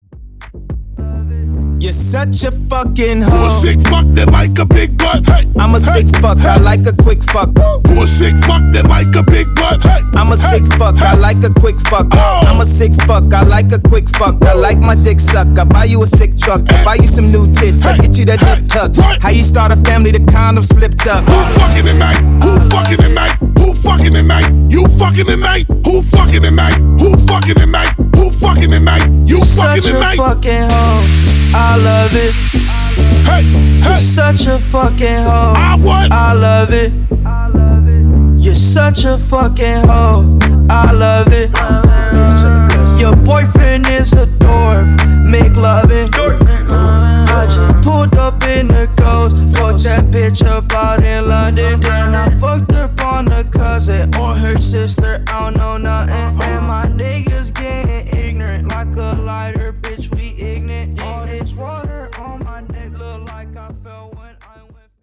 Genres: REGGAETON , TOP40 Version: Dirty BPM: 105 Time